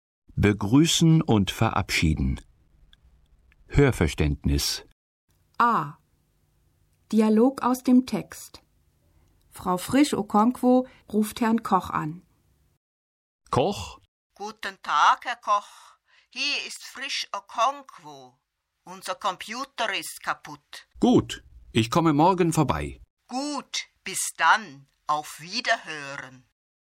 Dialog aus dem Text (431.0K)